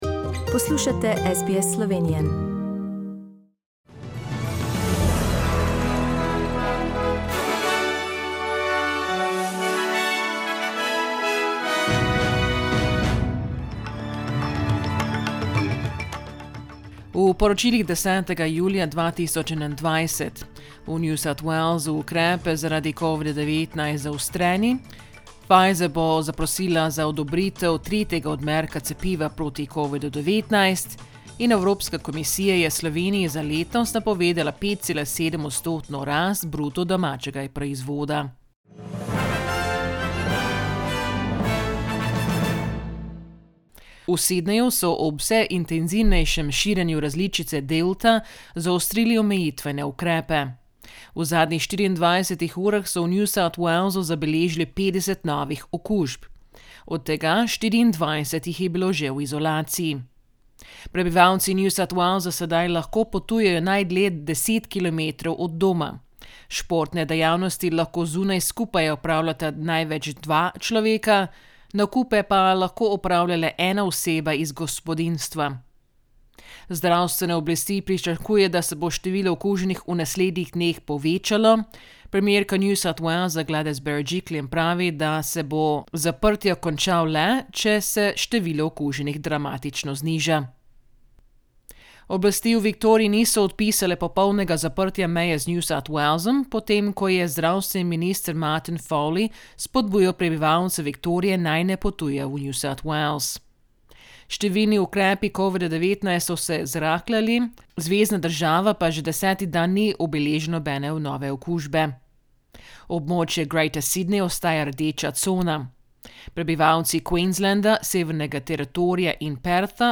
SBS News in Slovenian - 10th July, 2021